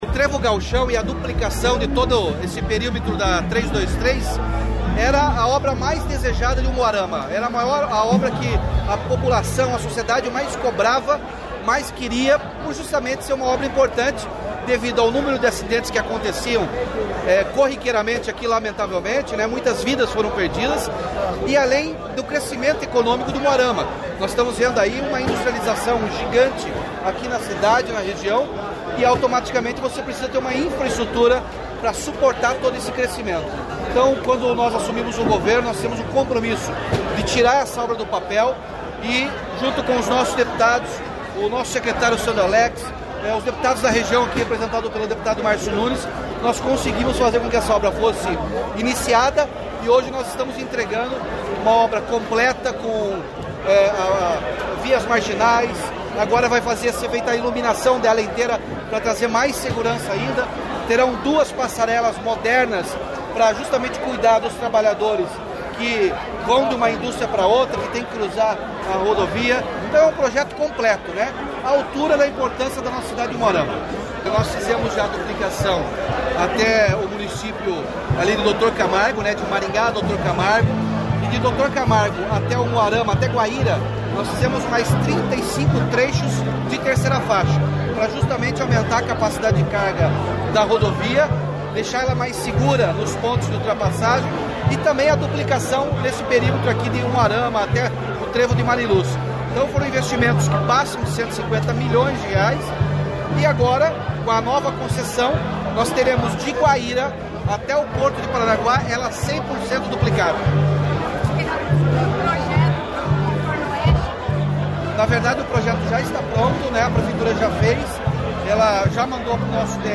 Sonora do governador Ratinho Junior sobre a inauguração do Trevo Gauchão e da duplicação da PR-323 em Umuarama